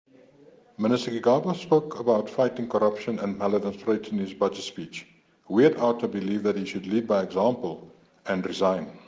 Soundbite in